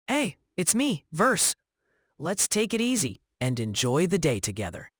NavTalk 提供多种高质量的语音合成风格，您可以通过 voice 参数自由选择数字人音色：
富有韵律，适合诗歌朗诵